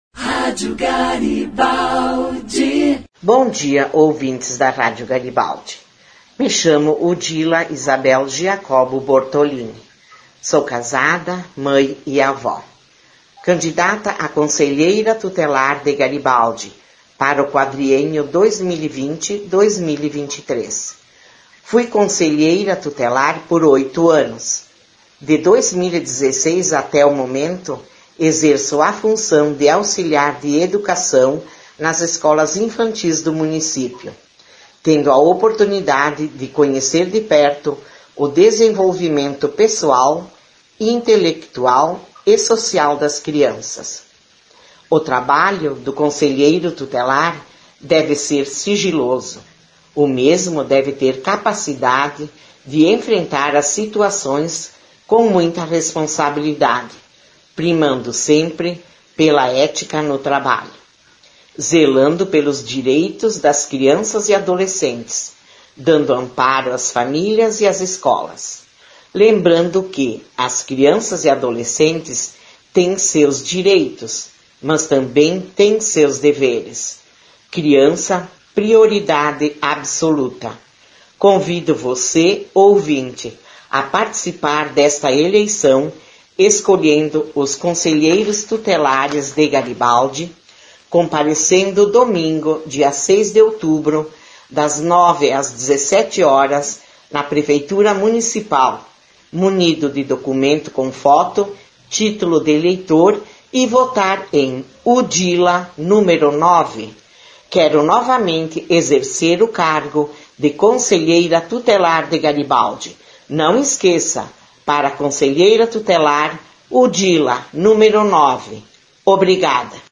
Cada um tinha direito a enviar um áudio de até 5 minutos para fazer sua apresentação e divulgar suas ideias.